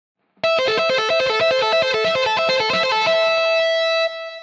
Гитарное упражнение 2
Аудио (100 УВМ)